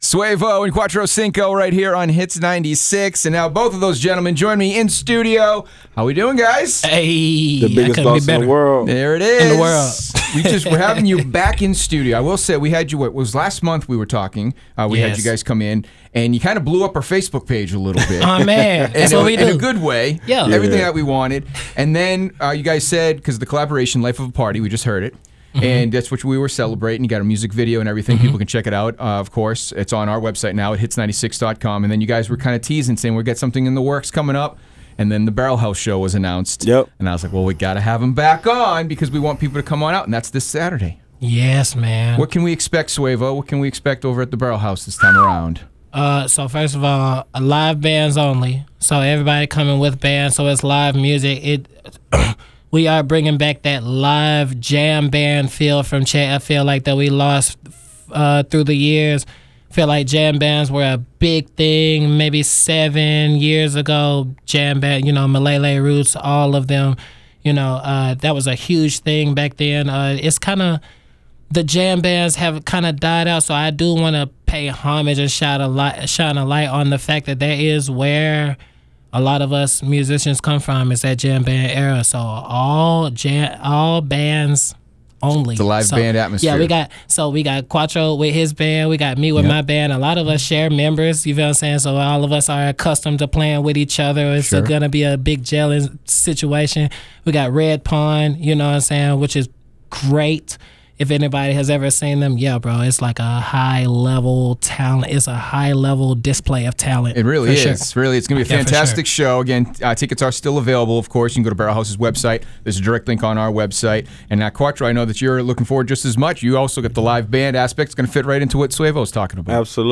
Interview-2.wav